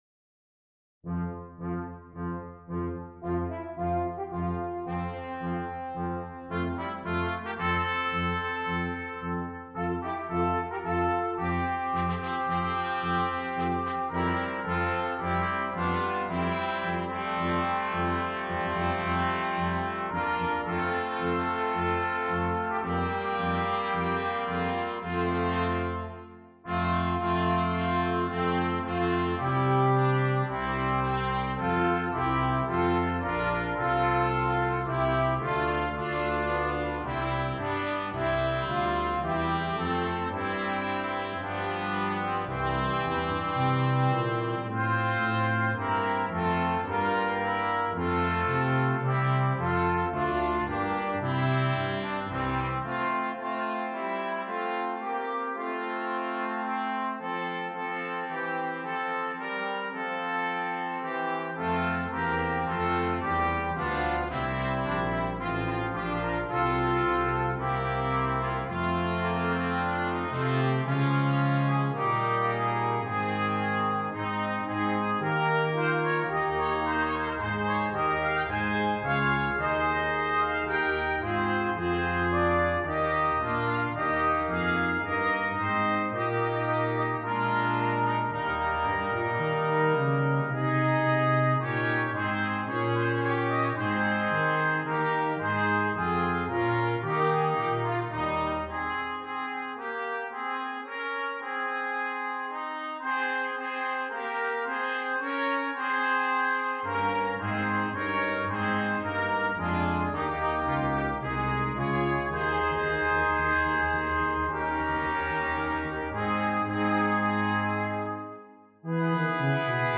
Brass Quintet
Traditional Carol